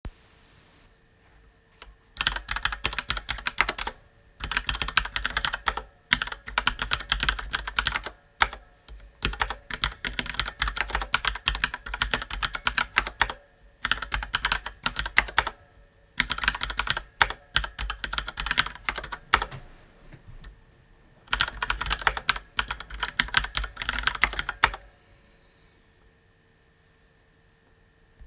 黄軸は軽く、快適な打鍵
キーボードのブランドによってはエンターキーだけちょっと音が違ったりもしますが、BlackWidow Chroma V2は全キー統一されています。
打鍵の音を録音してみました。
（私の叩く力がちょっと強くて乱暴な音になってしまいました…）